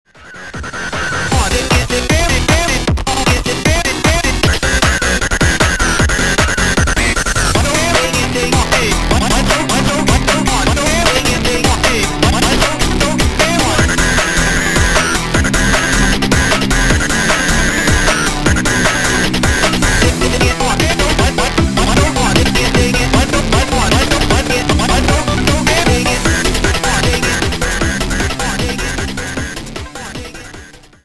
зарубежные клубные с басами